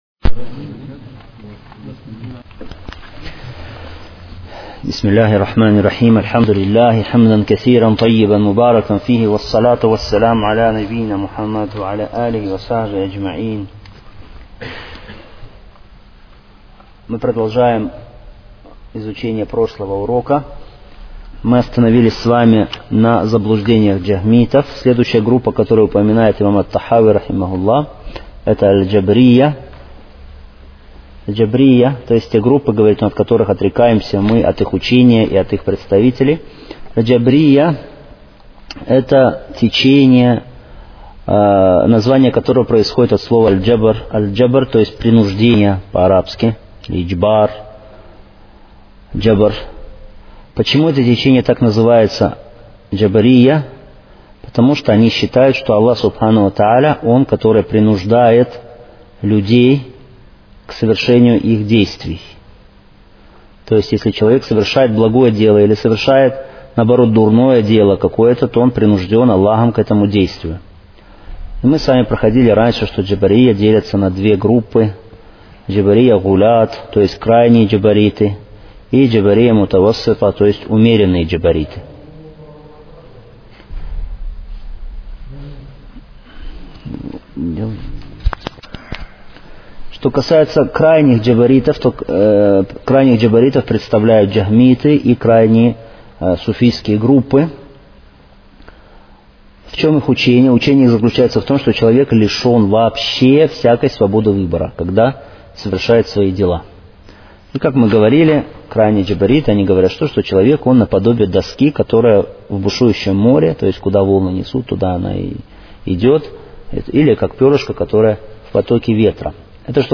Серия уроков в пояснении книги «Акида Тахавия».